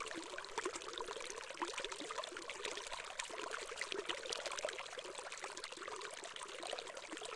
water.ogg